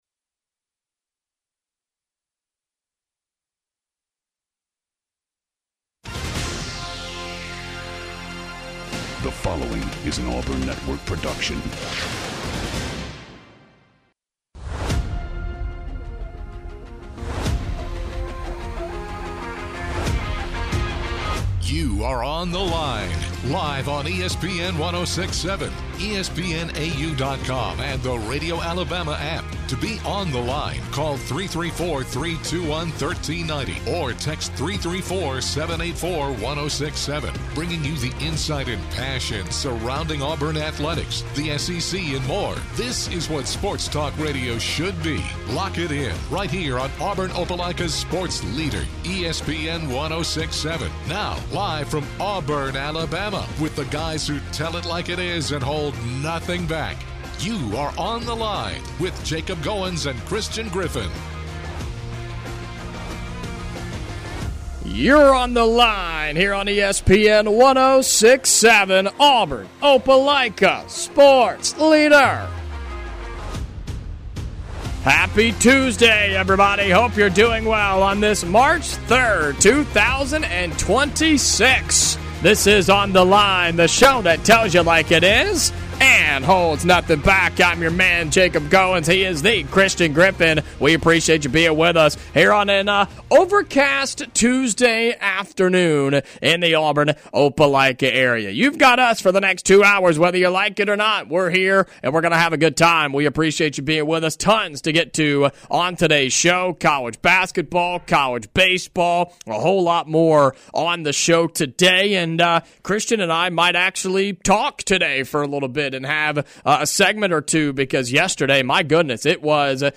joins over the phone